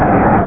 Cri de Monaflèmit dans Pokémon Rubis et Saphir.